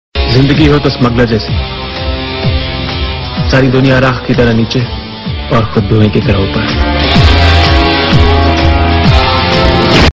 Dialogue Mp3 Tone